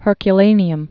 (hûrkyə-lānē-əm)